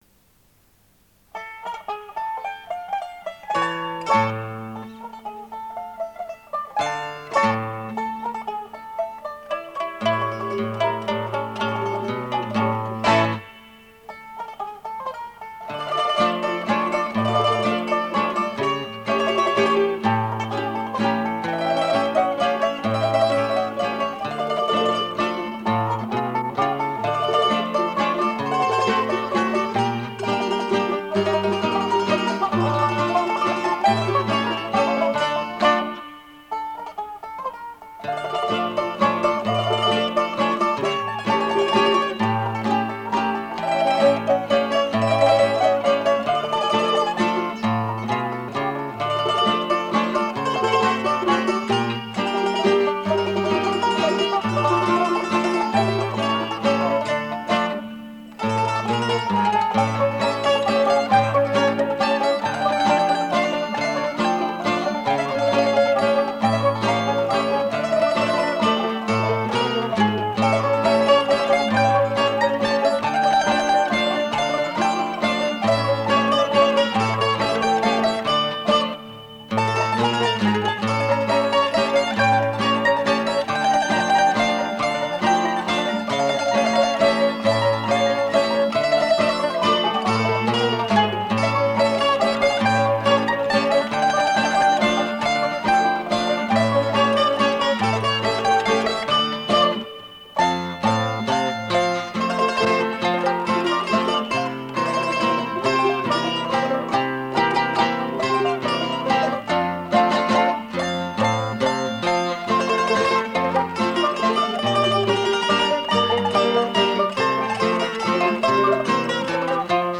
primo mandolino.
mandola
chitarra bolognese.